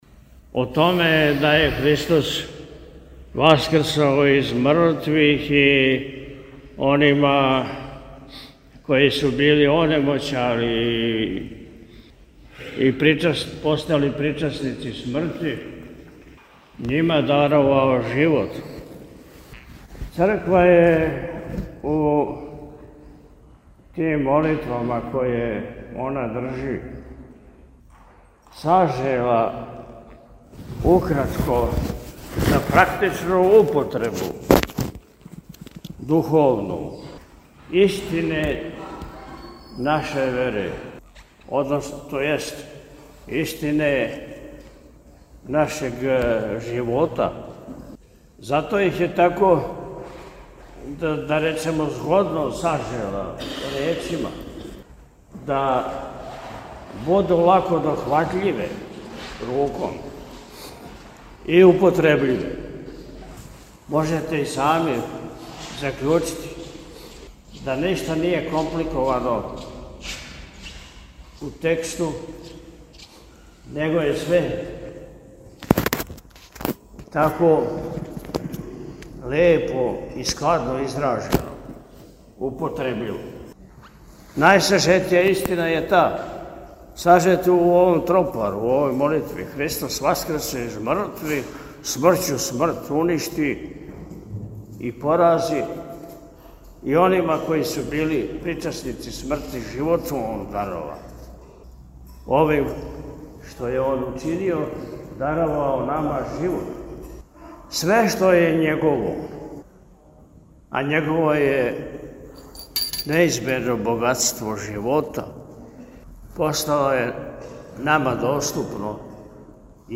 Беседу Митрополита Атанасија можете послушати овде:
Priboj-Utorak-Beseda.mp3